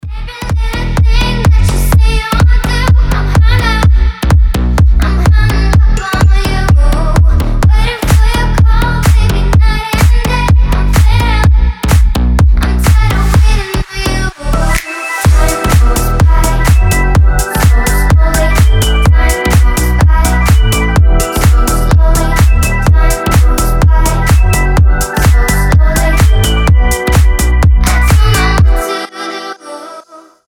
• Качество: 320, Stereo
retromix
Cover
slap house